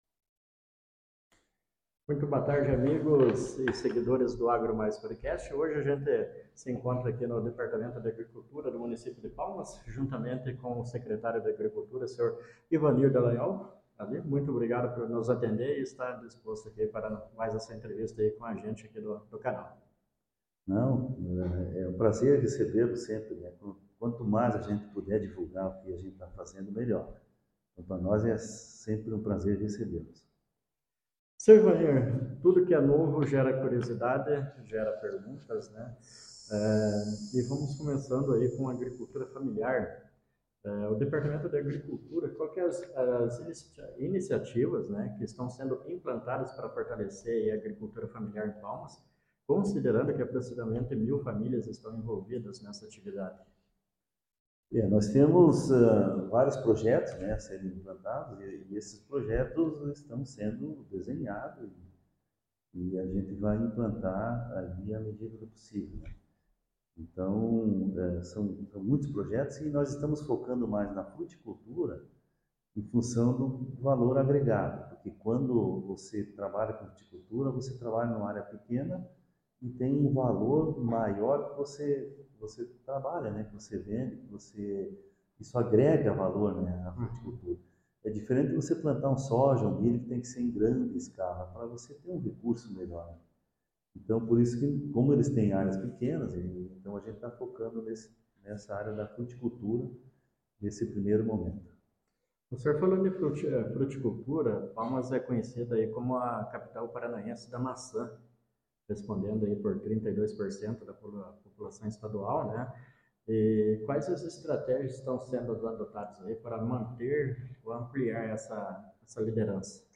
Na tarde desta terça-feira, o secretário de Agricultura do município de Palmas, Ivanir Dalanhol, recebeu a equipe do Agro+ Podcast para uma entrevista exclusiva.
entrevista-com-Ivanir-Dalanhol.mp3